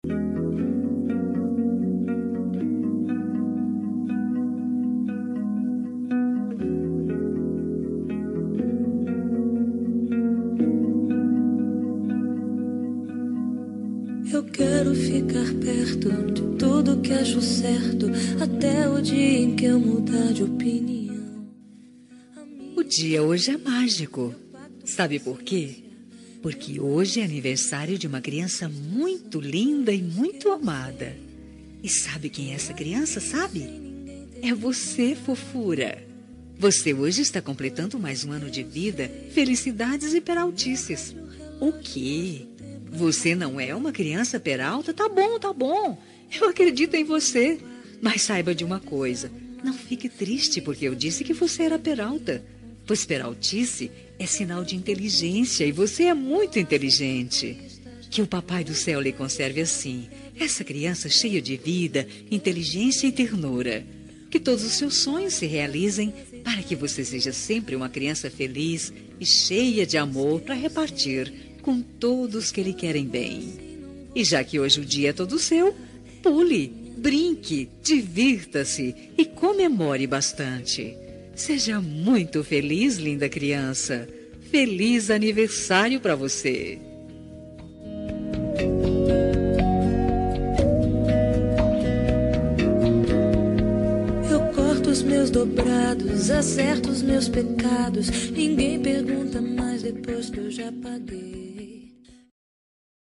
Aniversário Infantil – Voz Feminina – Cód: 8116